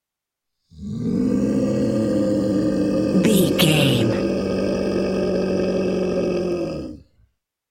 Monster scream big creature
Sound Effects
scary
ominous
eerie
angry
horror